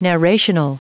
Prononciation du mot narrational en anglais (fichier audio)
Prononciation du mot : narrational
narrational.wav